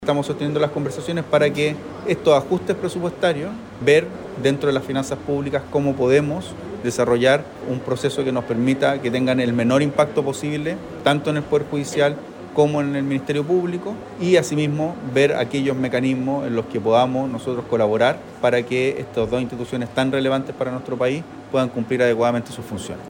Las declaraciones las entregó en su visita a Coronel, donde landó el nuevo Servicio de Reinserción Social Juvenil, que reemplazará al Sename.